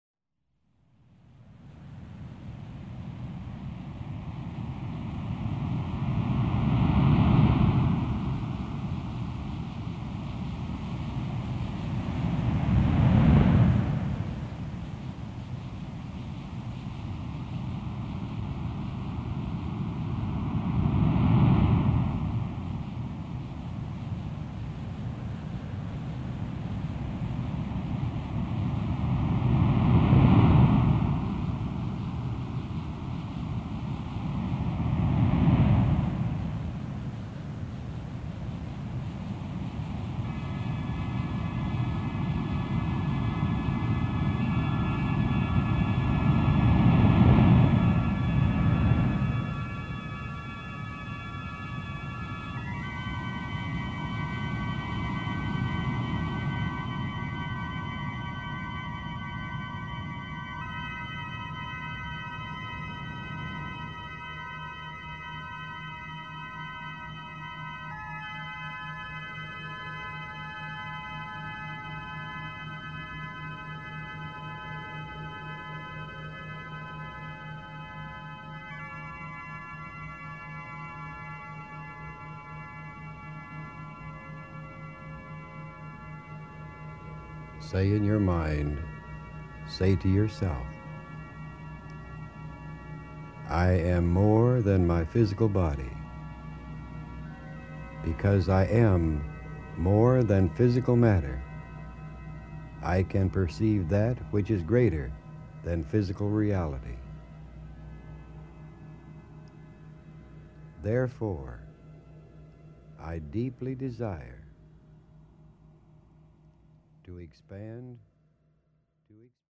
Deeply relaxing Hemi-Sync® sound patterns and a guided visualization assist you on this delightful inner journey.
The illumination of a peak experience – your moment of revelation – awaits you with this Hemi-Sync® classic, voiced by Robert Monroe. 32 min.